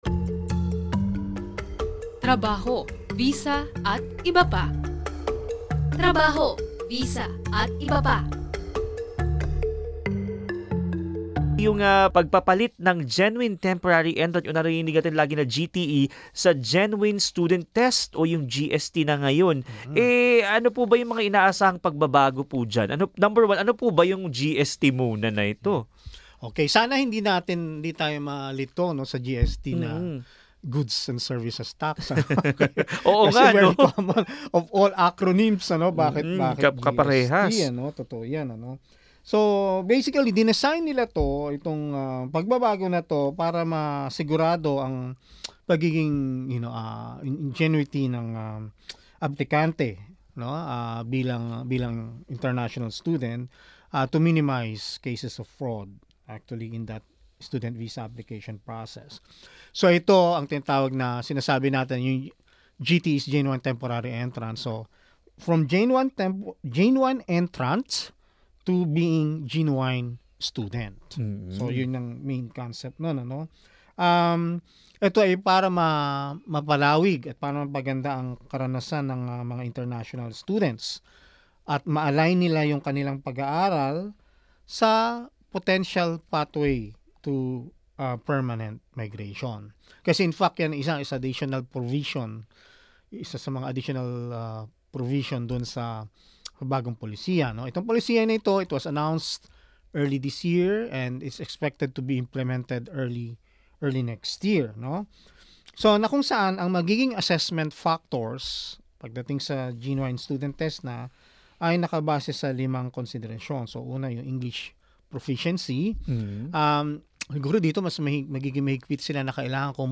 In this episode of 'Trabaho, Visa, atbp.,' a Migration Consultant explained one of the changes in the Australian student visa application requirements, which is the replacement of GTE with GST.